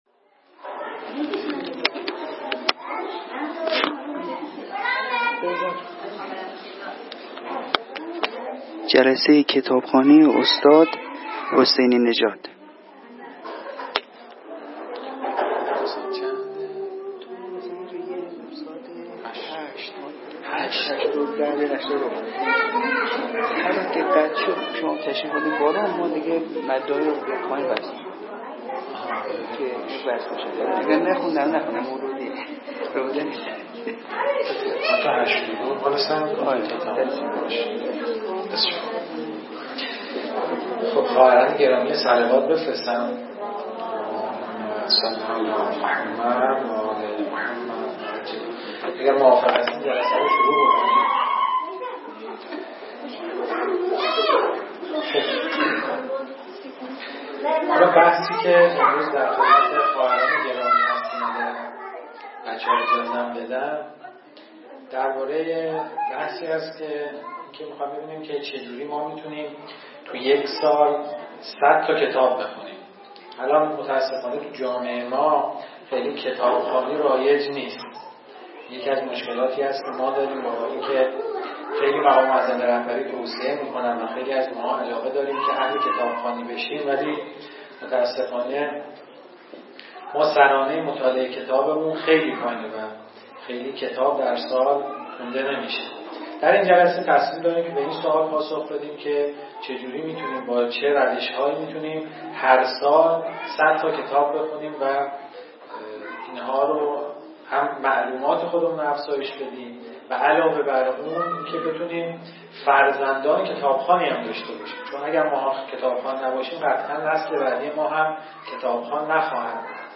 جلسه هیئت ویژه خواهران